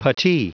Prononciation du mot puttee en anglais (fichier audio)